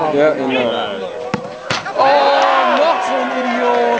Bei den aufgenommenen Torsequenzen fallen im Hintergrund immer wieder irgendwelche komischen Kommentare, man hört Jubelschreie oder wilde Diskussionen...